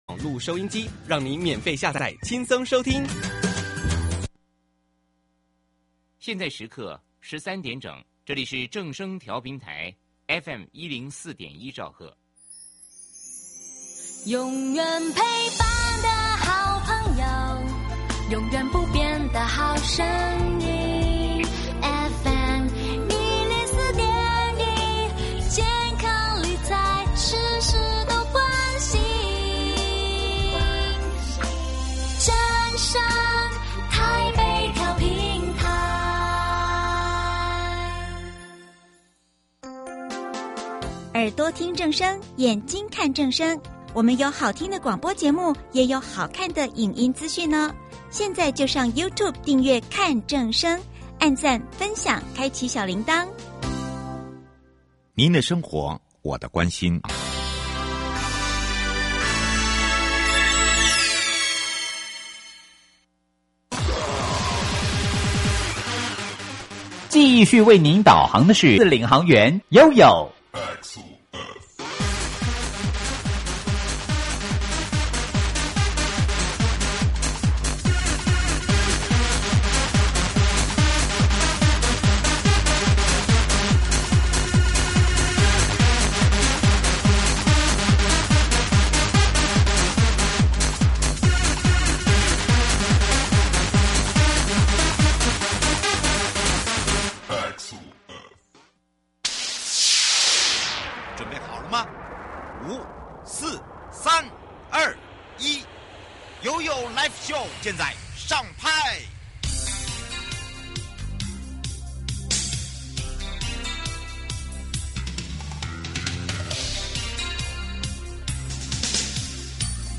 受訪者： 營建你我他 快樂平安行~七嘴八舌講清楚~樂活街道自在同行!(二) 持續精進公園綠地、路網及無障礙通行